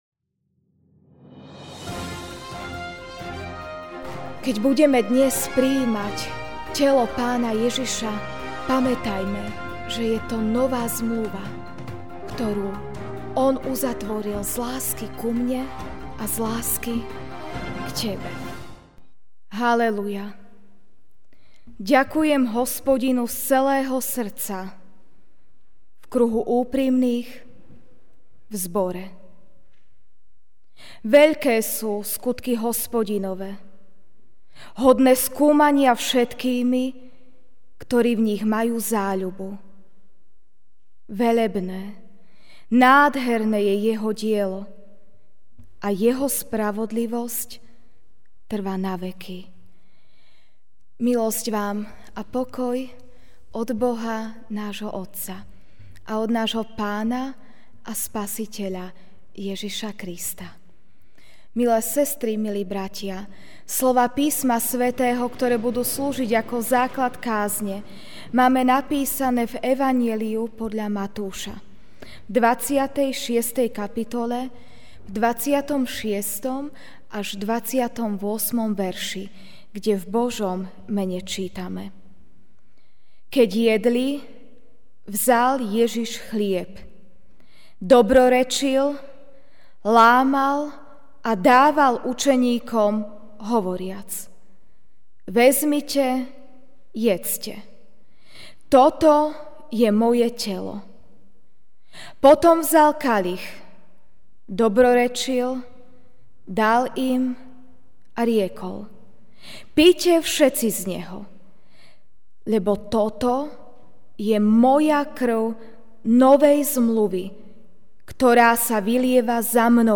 Večerná kázeň: Nová zmluva (Matúš 26,26-28) 'Keď jedli, vzal Ježiš chlieb, dobrorečil, lámal a dával učeníkom hovoriac: Vezmite, jedzte!